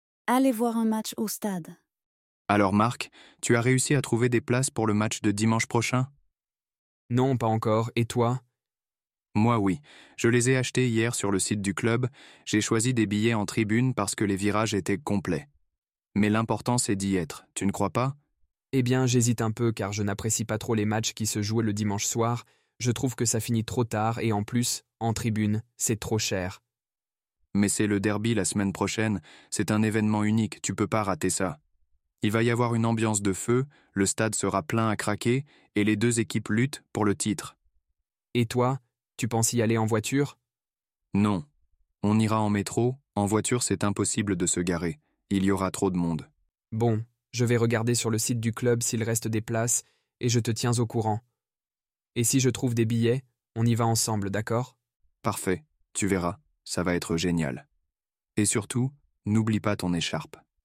Dialogues en Français